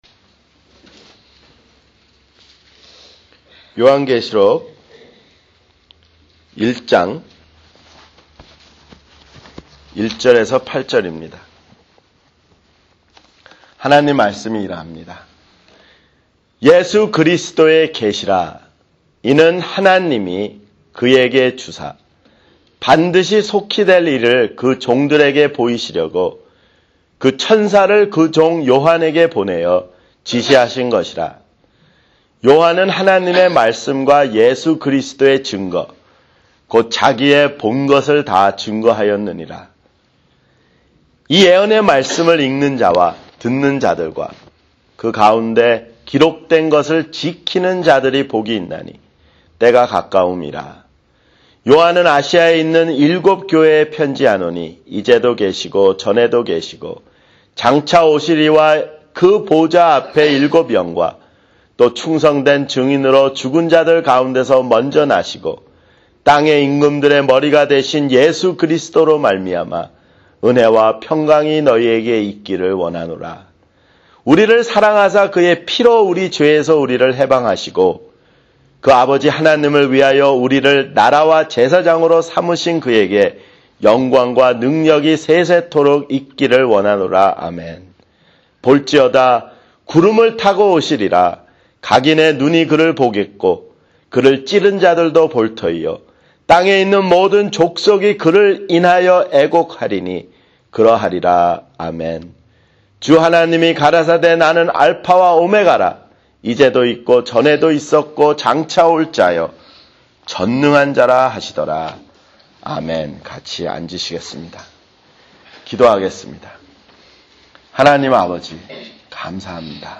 [주일설교] 요한계시록 (2)